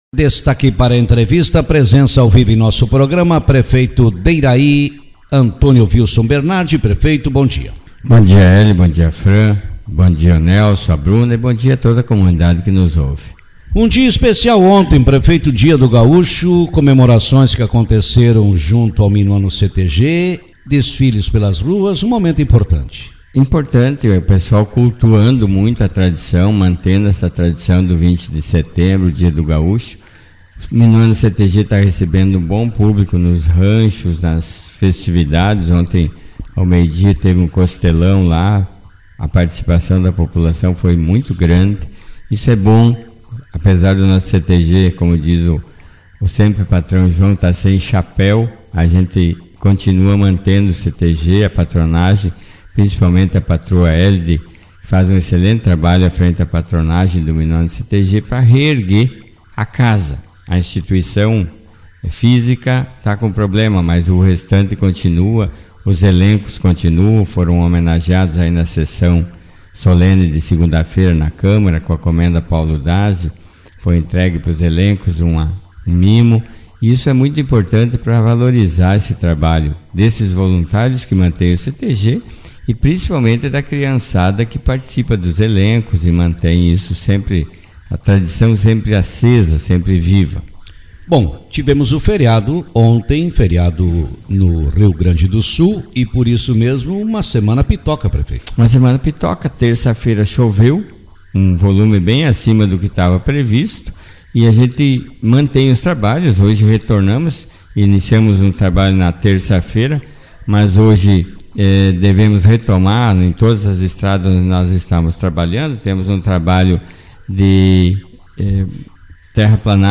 Prefeito de Iraí participa do Café com Notícias